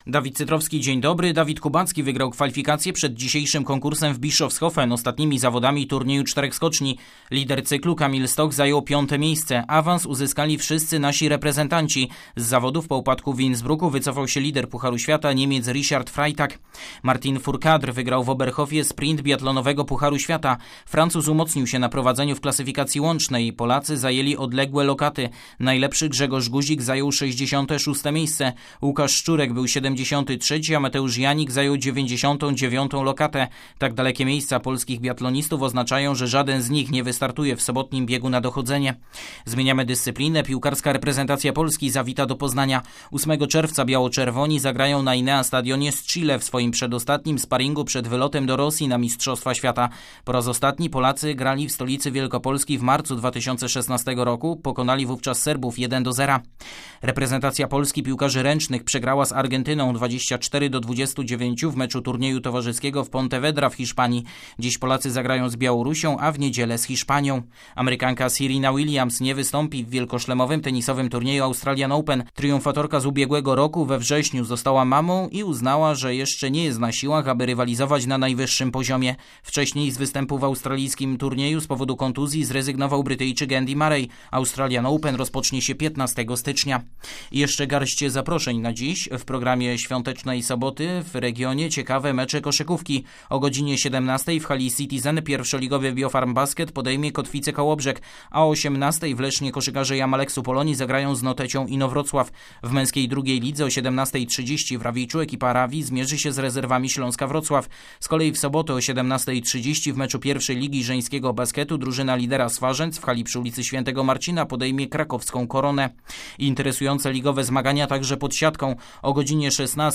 06.01 serwis sportowy godz. 7:05